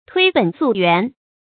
推本溯源 注音： ㄊㄨㄟ ㄅㄣˇ ㄙㄨˋ ㄧㄨㄢˊ 讀音讀法： 意思解釋： 探索根源，尋找原因。